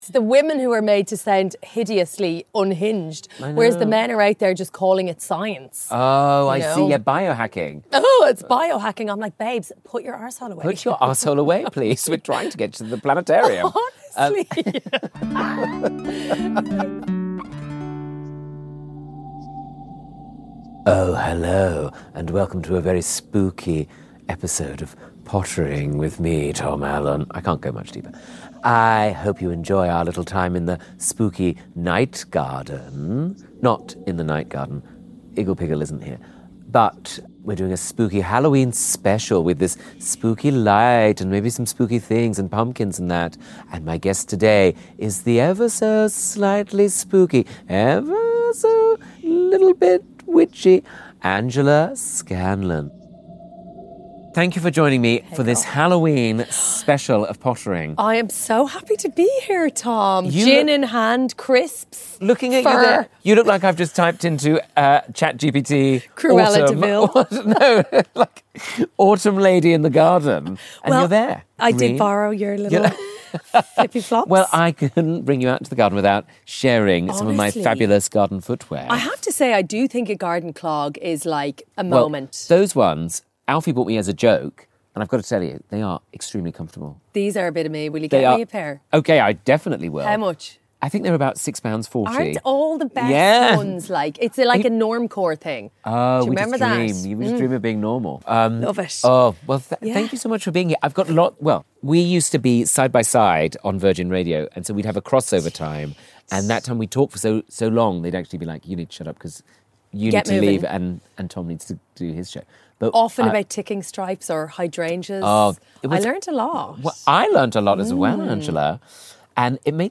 Pottering with Tom Allen Angela Scanlon Play episode October 30 52 mins Bookmarks View Transcript Episode Description On Pottering this week, things take a splendidly spooky turn as we celebrate Halloween in the garden with broadcaster, author and bewitching presence Angela Scanlon!